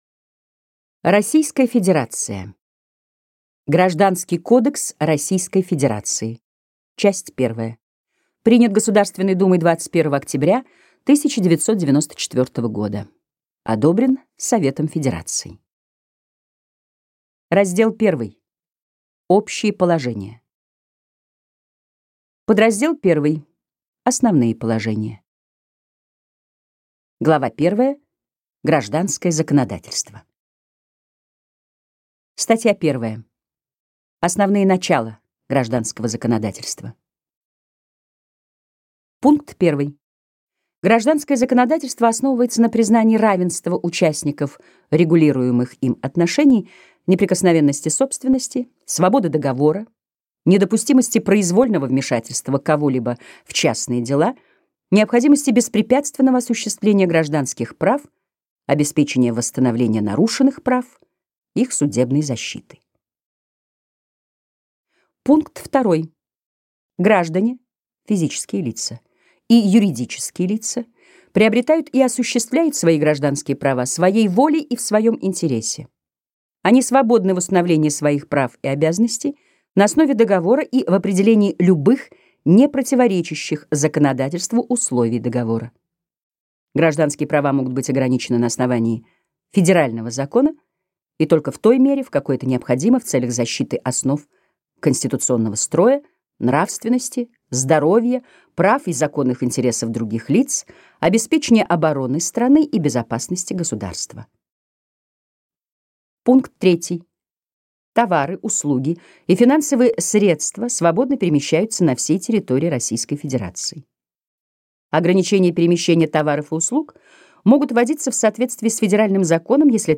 Аудиокнига Гражданский кодекс Российской Федерации. Часть 1 | Библиотека аудиокниг